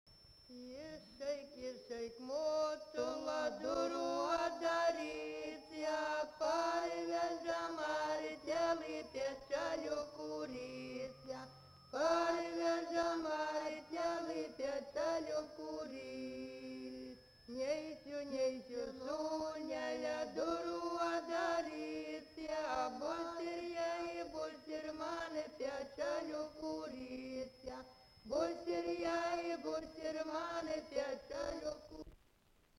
Subject daina
Erdvinė aprėptis Viečiūnai
Atlikimo pubūdis vokalinis